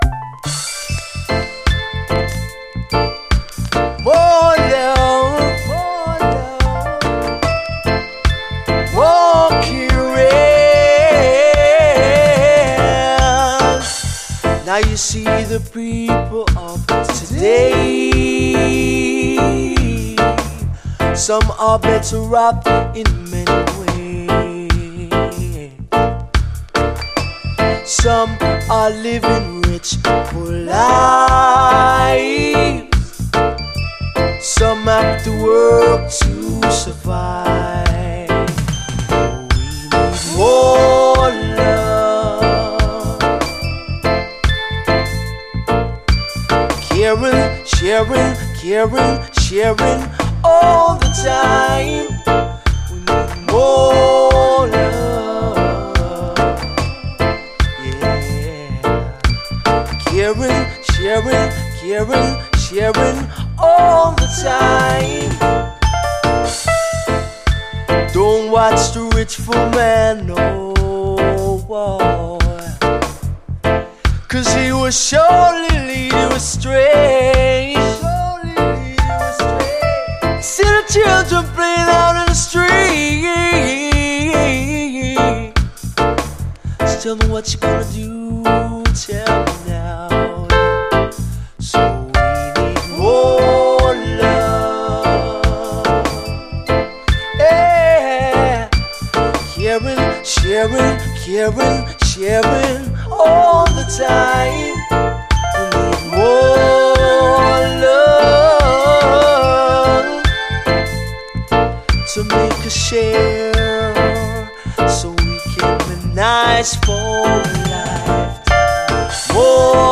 盤面細かいスレあり、所々プチノイズあり。下の方の試聴ファイルがこの盤からの録音です
女性ヴォーカル＆男性ヴォーカルが超甘く切ない素晴らしいデュエット！